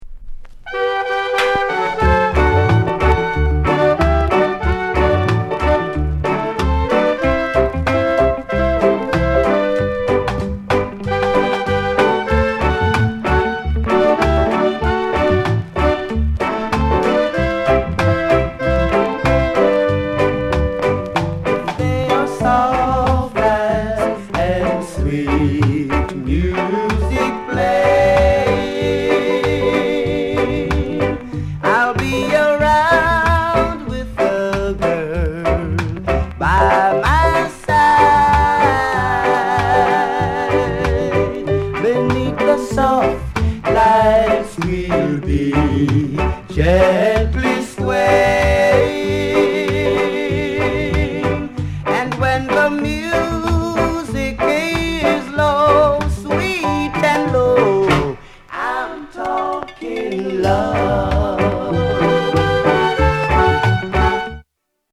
ROCKSTEADY